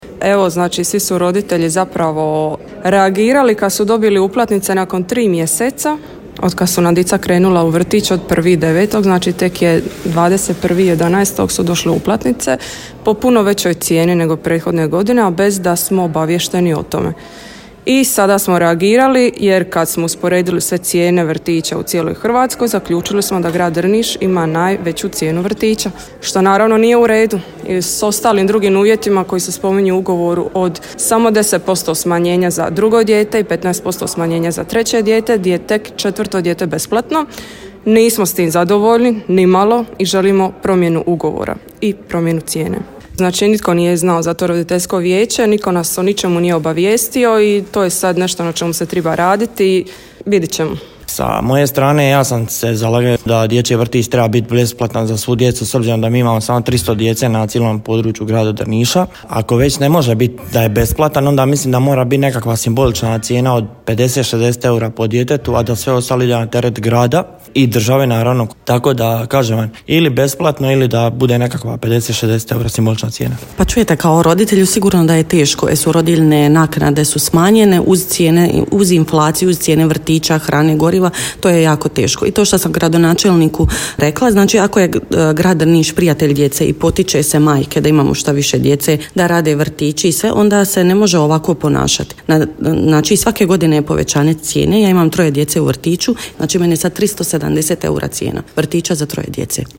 Evo što su nam kazali roditelji: